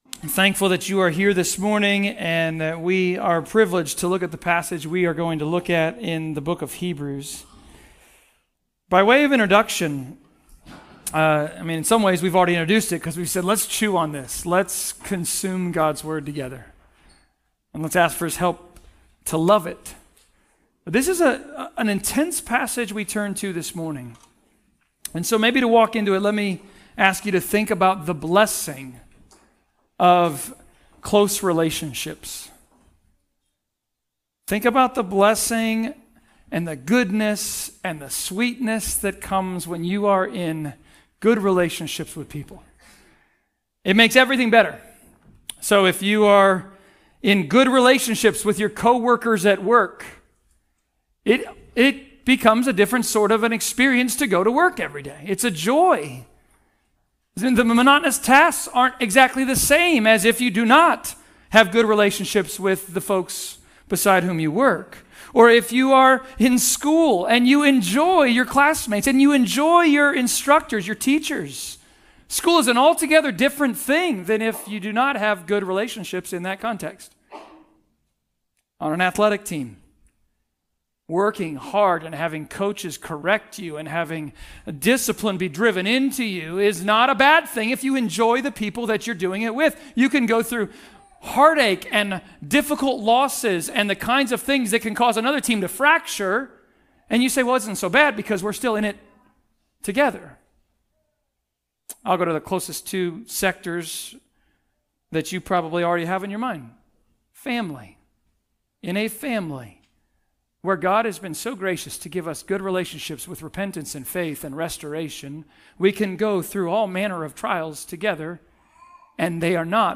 Hebrews Passage: Hebrews 10.26-39 Service Type: Sermons « WE HAVE CONFIDENCE…